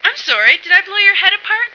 flak_m/sounds/female1/int/F1sorry.ogg at df55aa4cc7d3ba01508fffcb9cda66b0a6399f86